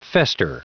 Prononciation du mot fester en anglais (fichier audio)
Prononciation du mot : fester